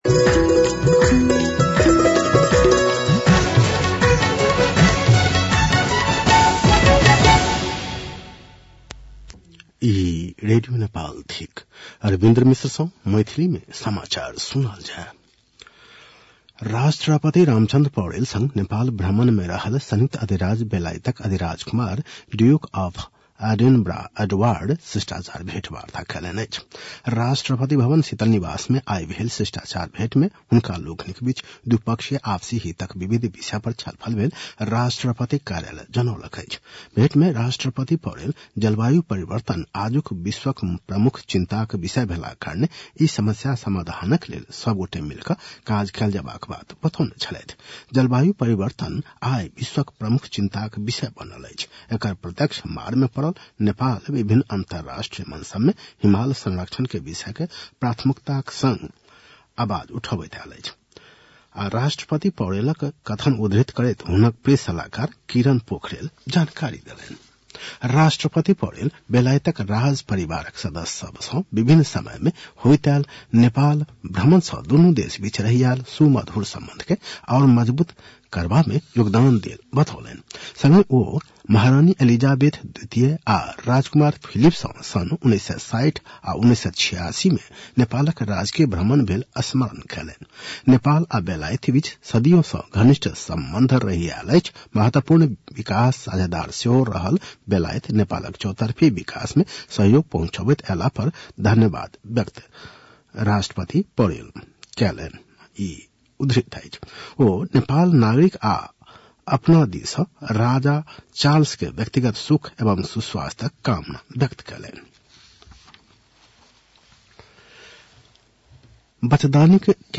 मैथिली भाषामा समाचार : २३ माघ , २०८१